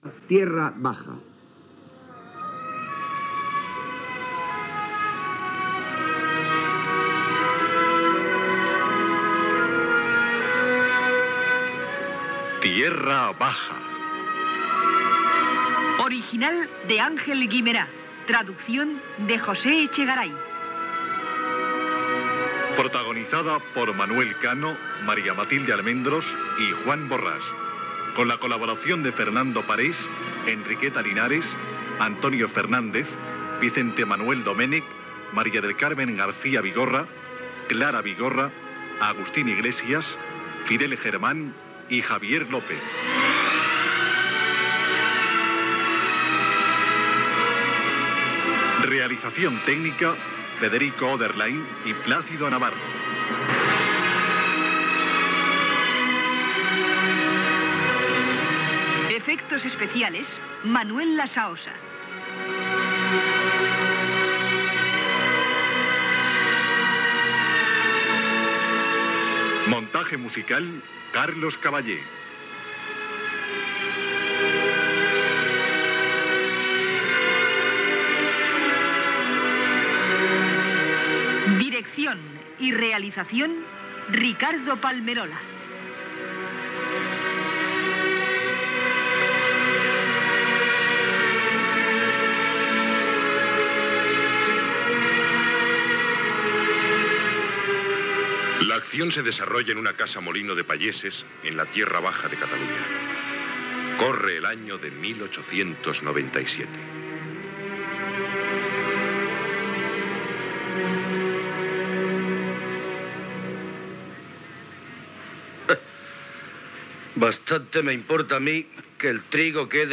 Adaptació radiofònica de la versió en castellà de "Terra baixa" d'Àngel Guimerà.
Gènere radiofònic Ficció